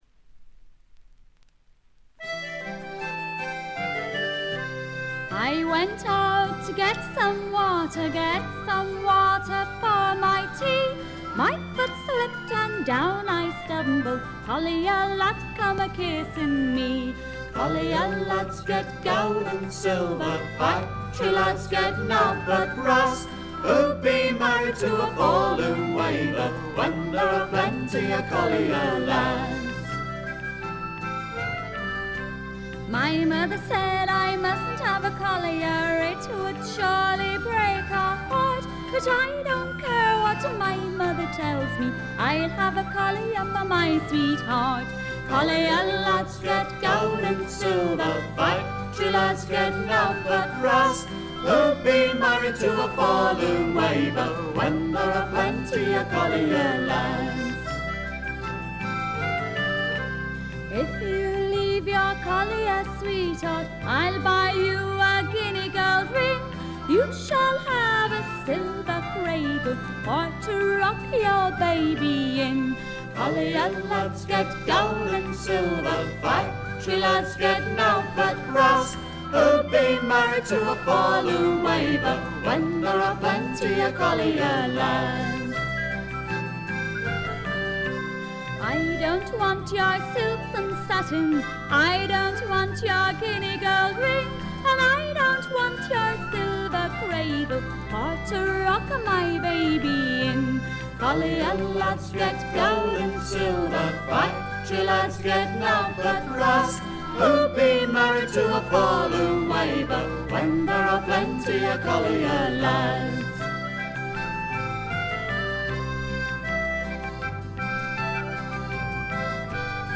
部分試聴ですがチリプチ、プツ音少々。
男女ヴォーカルでいたってフツーのフォークを演っていますが、こういうのもいいもんです。
試聴曲は現品からの取り込み音源です。